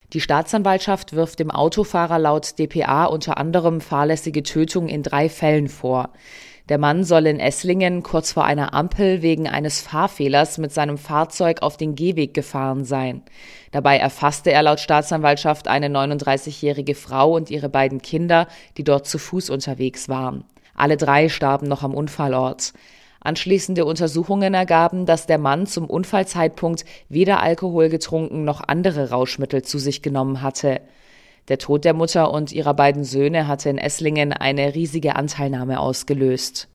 "Das war ein tiefgreifendes Ereignis für die ganze Stadt Esslingen", erzählt Oberbürgermeister Matthias Klopfer (SPD) im SWR-Interview.